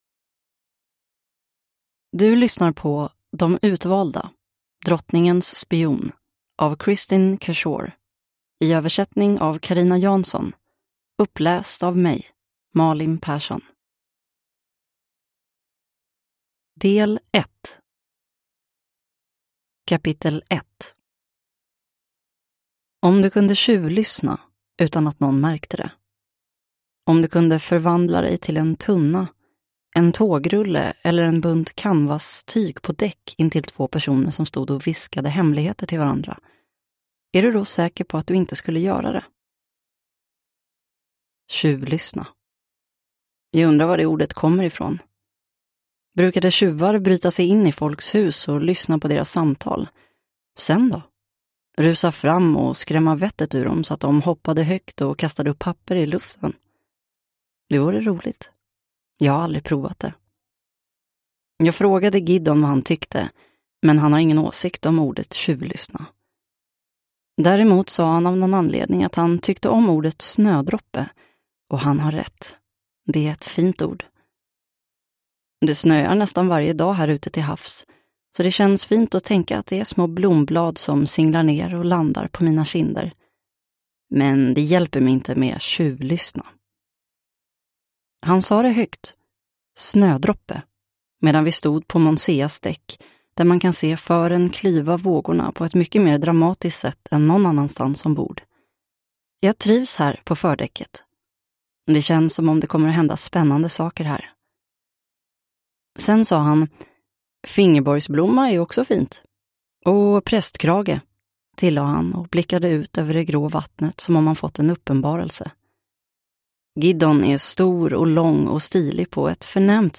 Drottningens spion – Ljudbok – Laddas ner